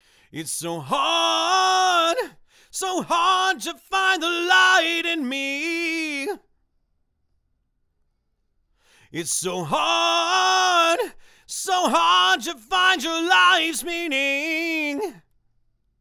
The detail comes up a lot with the mod as does the bandwidth
For Vocals a Rode Ntk was used. For guitar an Oktave Ml52 Ribbon mic with a 10K boost on the preamp set to 3 o'clock
Revive Audio Modified WA73 on Rock Vocal